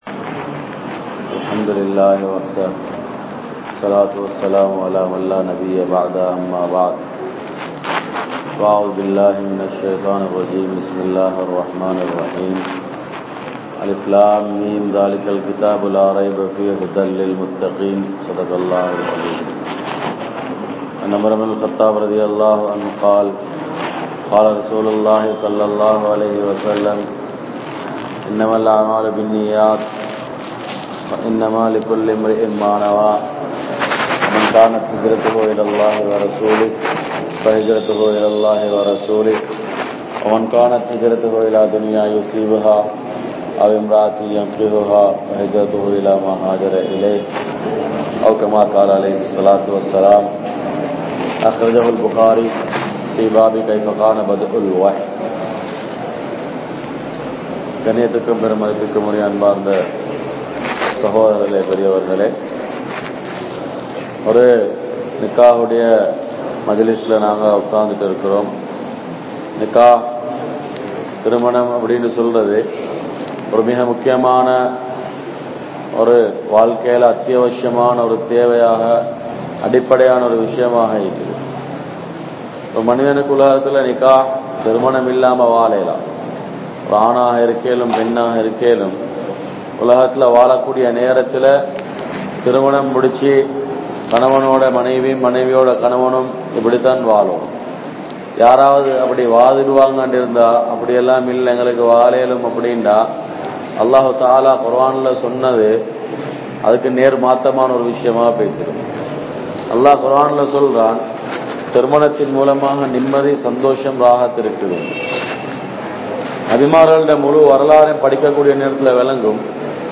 Nimmathiyai Tholaiththa Manitharhal (நிம்மதியை தொலைத்த மனிதர்கள்) | Audio Bayans | All Ceylon Muslim Youth Community | Addalaichenai
Safa Jumua Masjidh